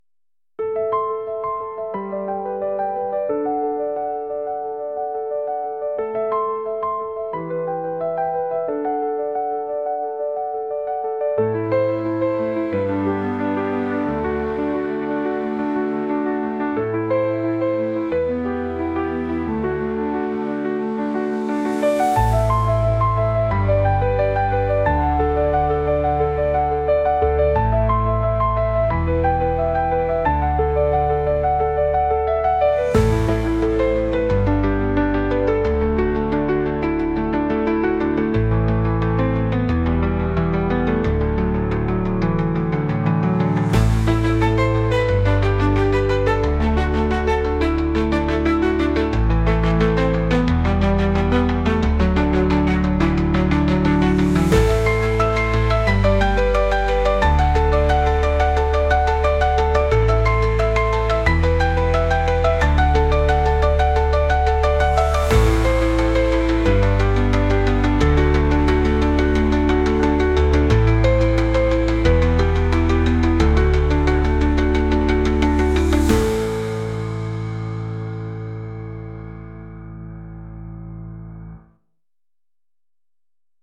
ピアノソロから始まり後半はバイオリンも絡む短めの曲です。